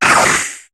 Cri de Mangriff dans Pokémon HOME.